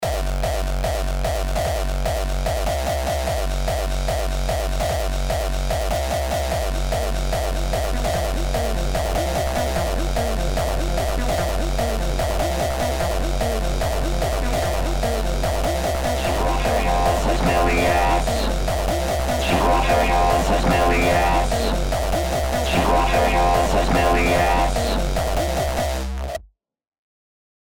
:D Ist das überhaupt Hardtechno? Wenn Du das Tempo noch etwas anpasst, dann würde ich das unter Acidcore einsortieren...